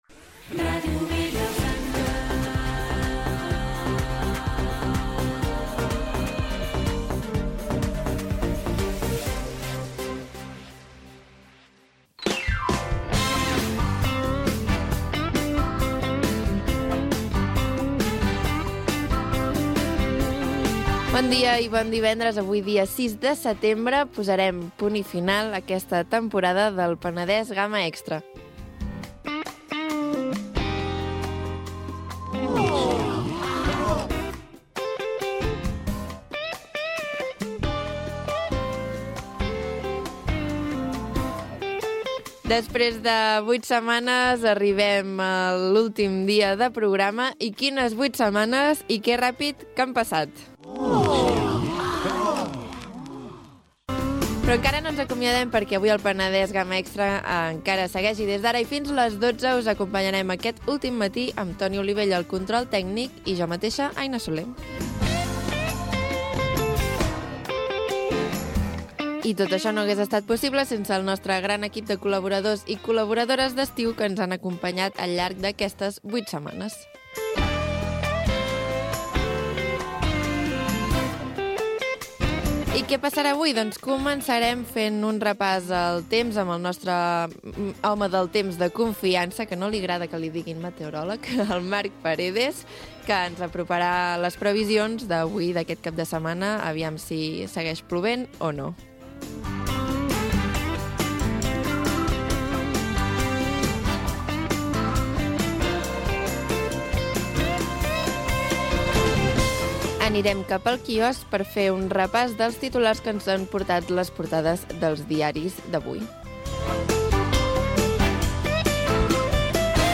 Indicatiu de l'emissora, presentació de l'últim programa de la temporada, equipm sumari, indicatiu, previsió meteorològica
Entreteniment
FM